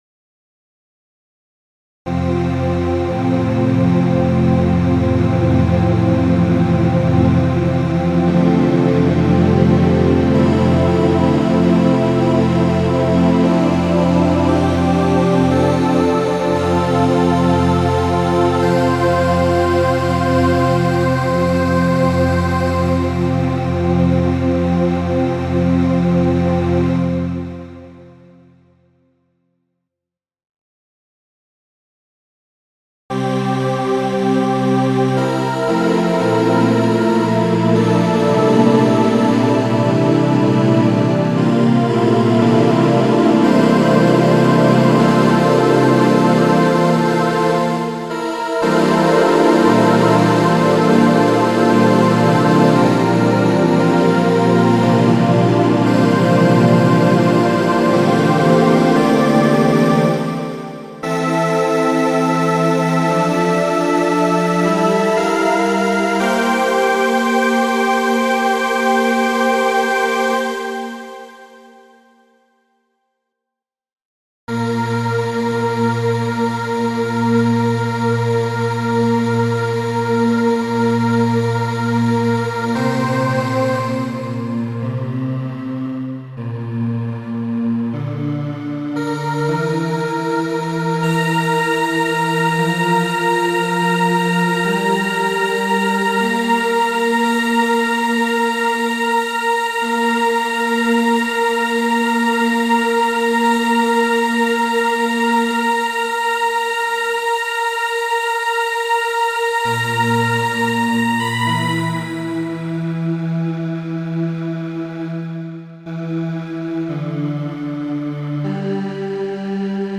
(CHORUS) false charpath 3 setlinewidth stroke
\center-column {\italic "f major; c d e f g a bes" } }